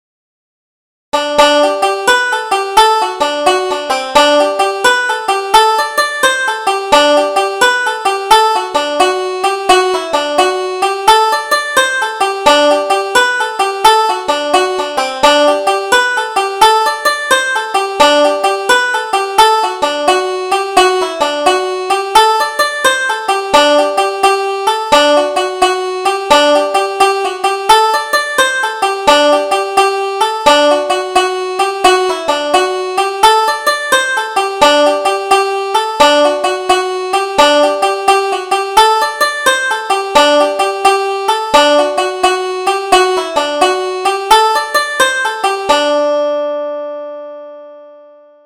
Double Jig: Strop the Razor - 1st Setting